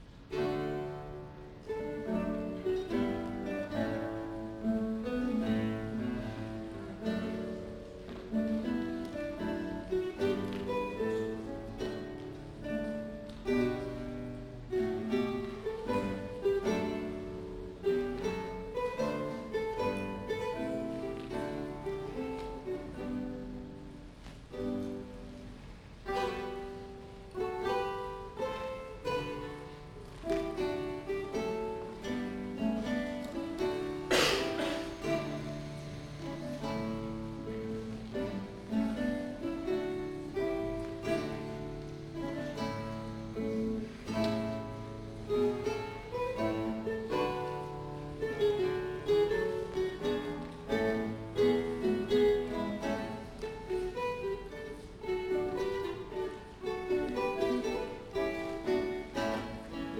1992年12月18日　於 市民プラザ アンサンブルホール
二重奏
4_duet_s.mp3